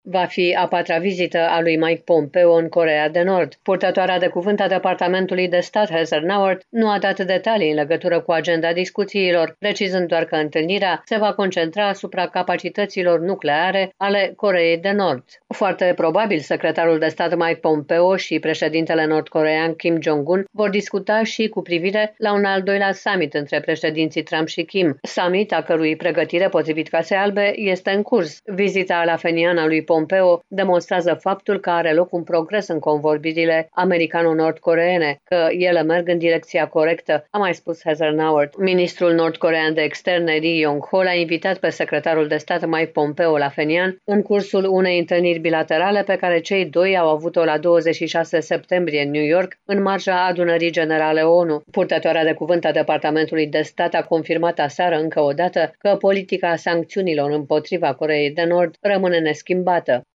transmite din Washington